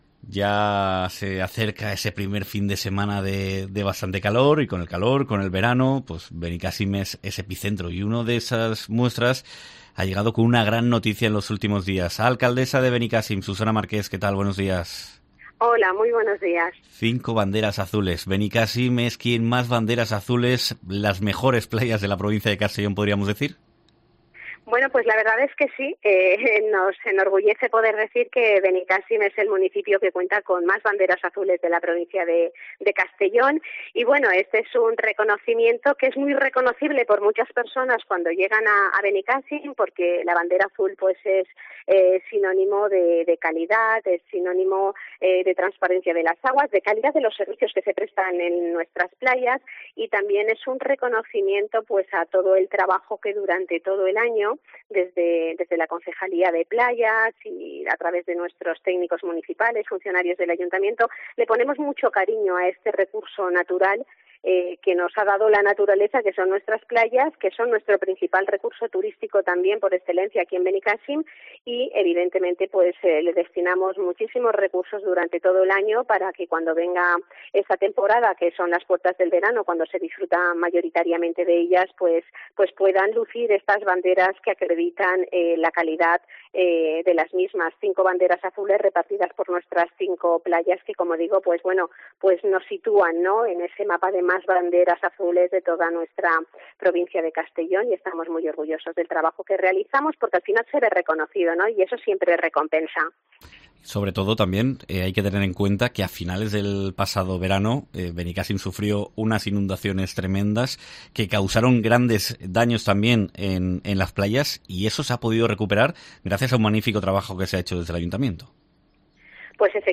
Entrevista
Benicàssim luce cinco banderas azules y reclama más tratamientos contra los mosquitos... algunos de los temas que analiza en la Cadena COPE la alcaldesa, Susana Marqués